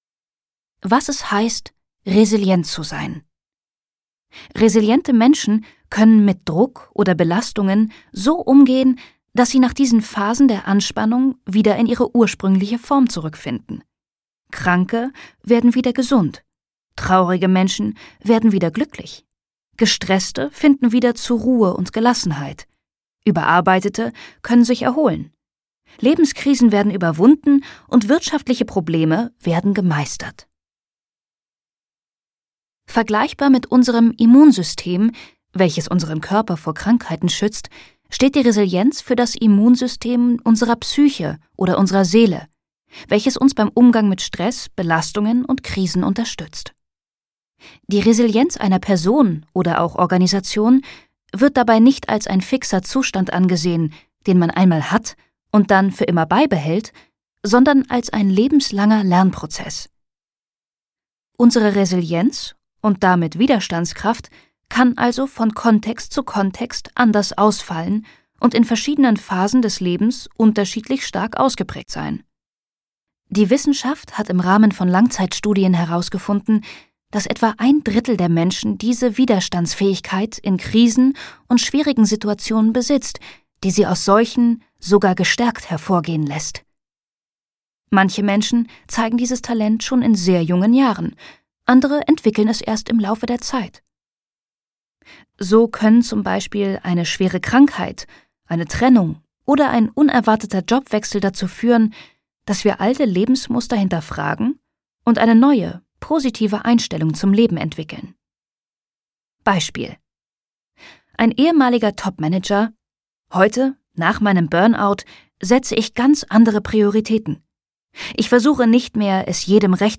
Gekürzt Autorisierte, d.h. von Autor:innen und / oder Verlagen freigegebene, bearbeitete Fassung.
Zur Sprecherin